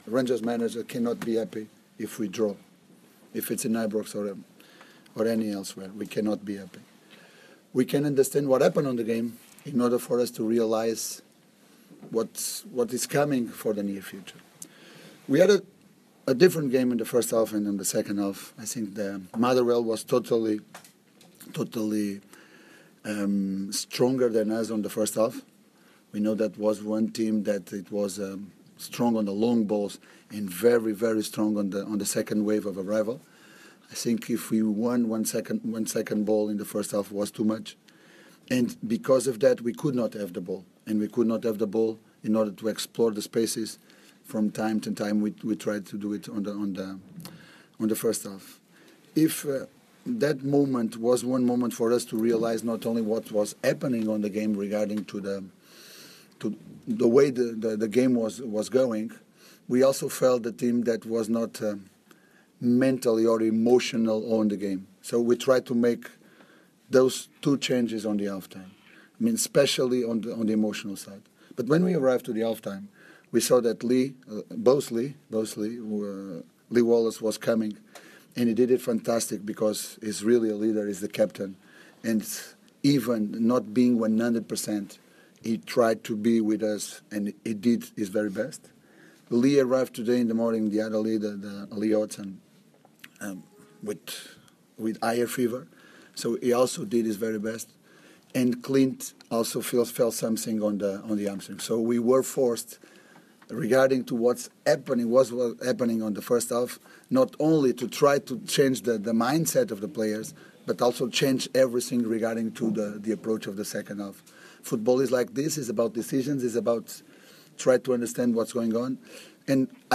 Pedro Caixinha & Barrie McKay speak after Rangers draw 1-1 at home to Motherwell